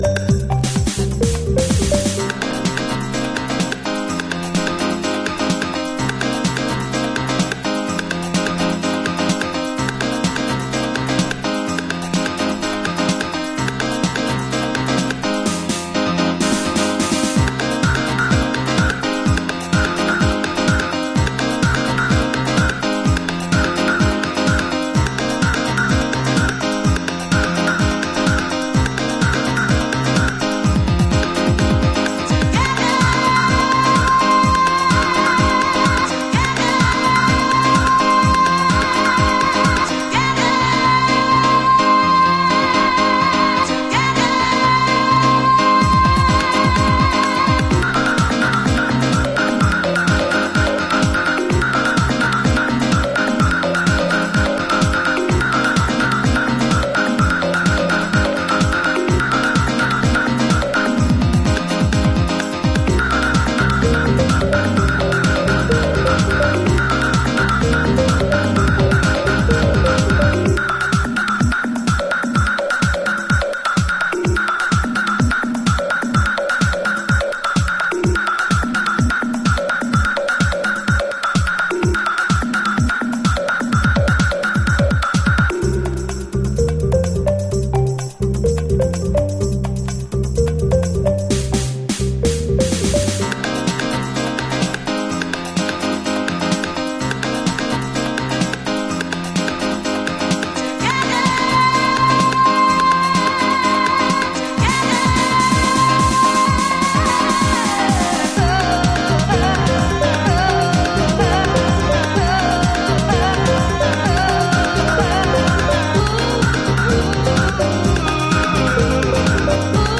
シカゴ伝統のパーカッシブジャックビート